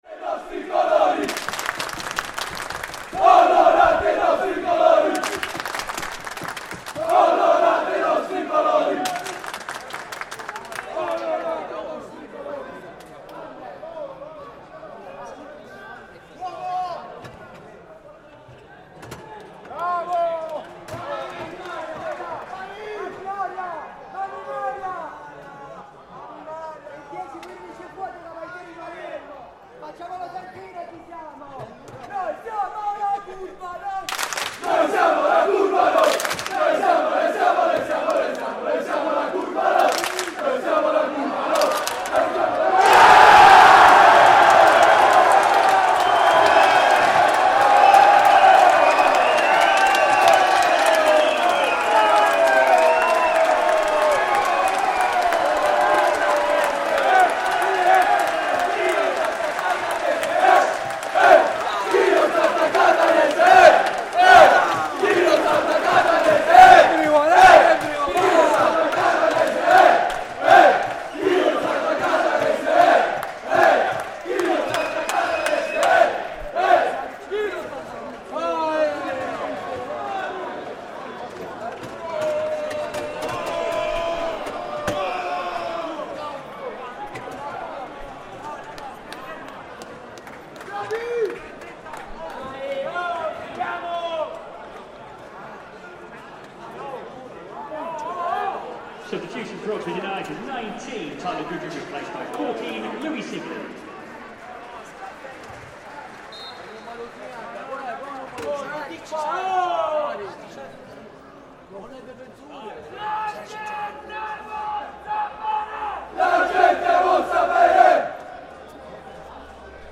Oxford United 0-2 Palermo, 3 August 2024 at the Kassam Stadium, Oxford. We're in amongst the travelling Sicilian ultras as they make a party out of their day out in Oxford, with constant singing and chanting (often at the expense of local rivals Catania from a distance).